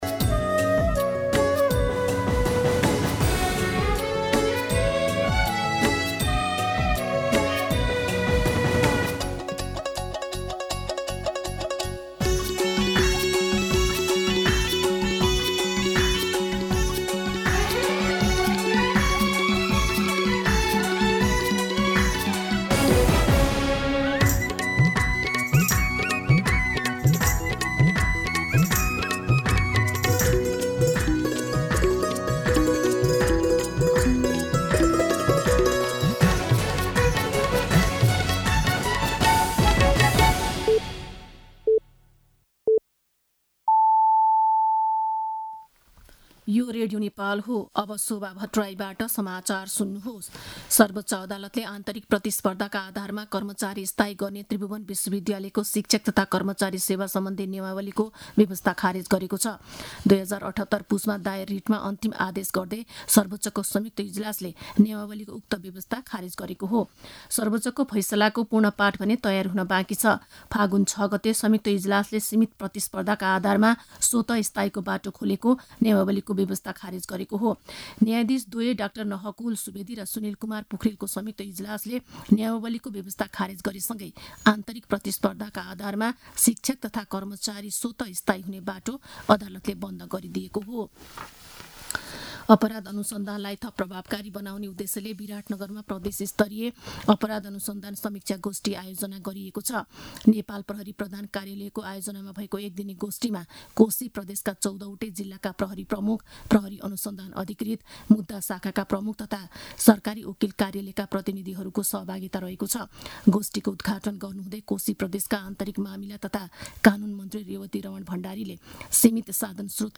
दिउँसो १ बजेको नेपाली समाचार : १३ फागुन , २०८१